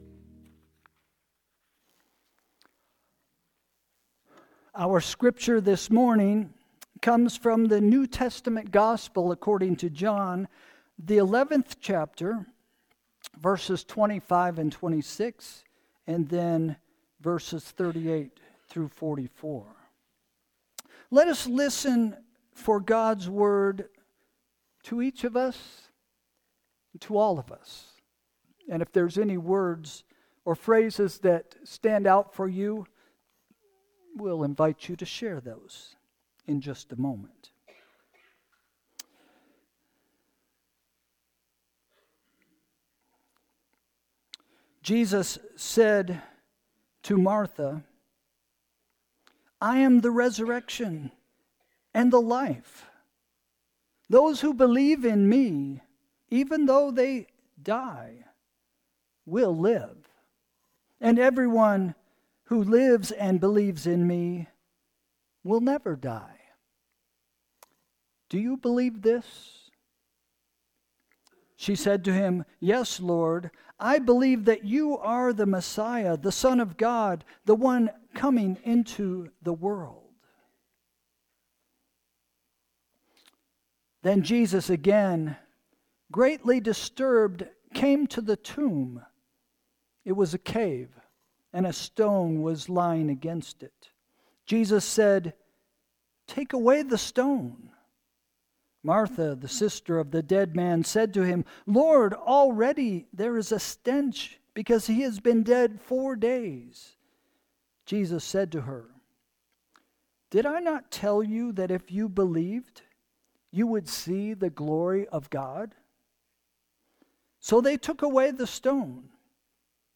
Sermon – Easter, April 20, 2025 – “Ready for Resurrection” – First Christian Church